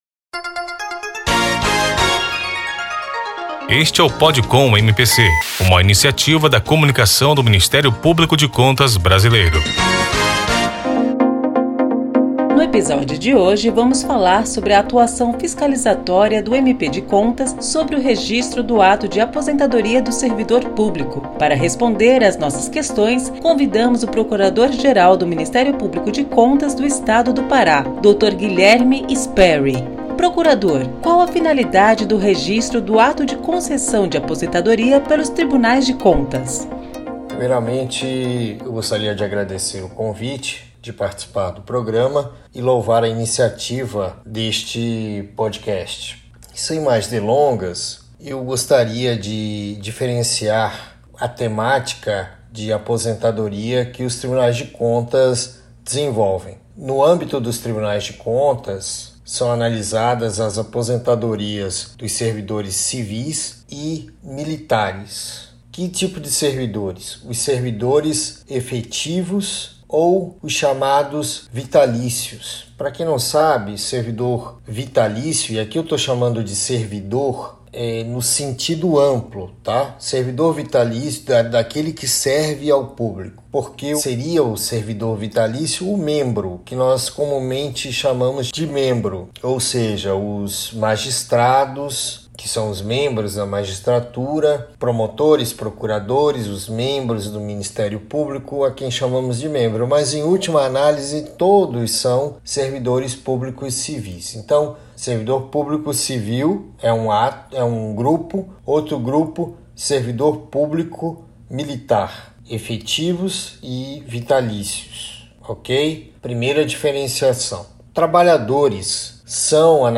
Para esclarecer a dúvida, convidamos o Procurador-Geral de Contas do Estado do Pará, Guilherme da Costa Sperry, para o PODCOM MPC, uma iniciativa da Comunicação do Ministério Público de Contas Brasileiro. Neste Podcast, o Procurador-Geral do MPC/PA explica o trâmite desse tipo de processo e o motivo pelo qual as aposentadorias passam pelo crivo do MPC.